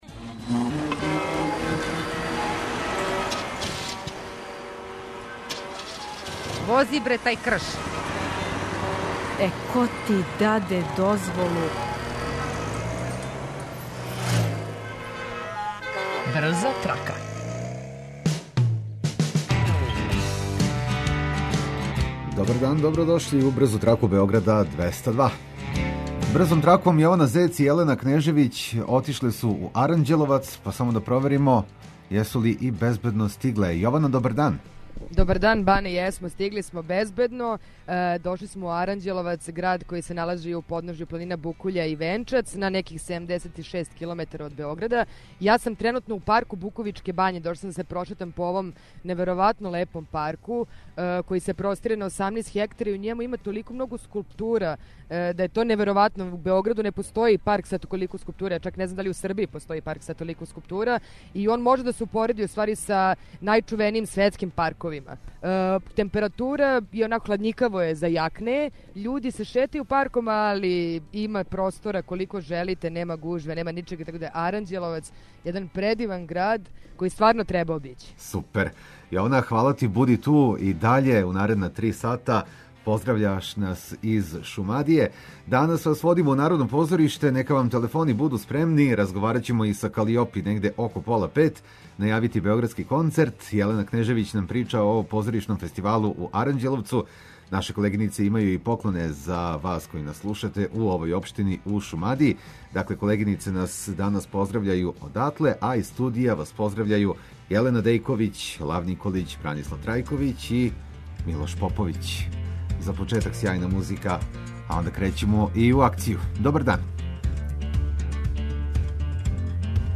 Слушаоци репортери јављају новости из свог краја, па нам се јавите и ви.